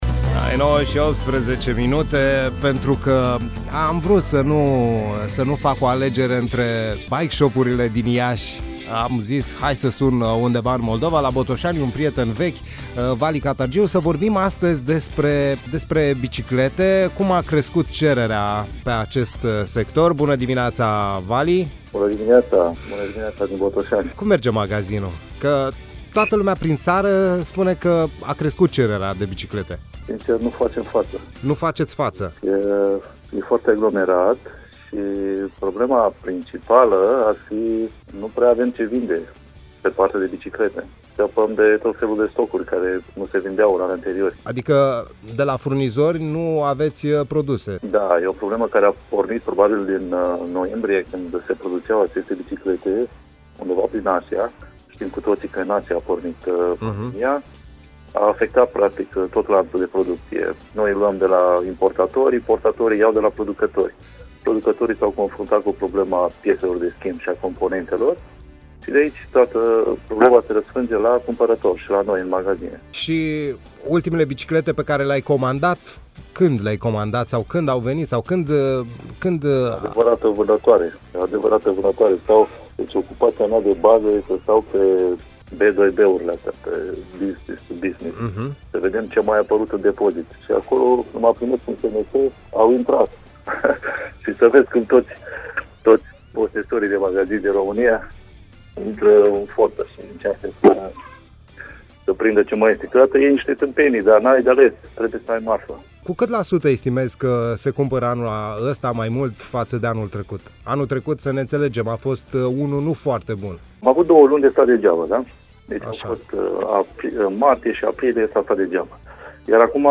Românii dau mașina pe bicicletă, stocurile sunt aproape terminate, iar proprietarii lanțurilor de magazine de biciclete spun că au reușit să vândă în perioada această stocuri vechi. La Play the Day am vorbit cu un comerciant de biciclete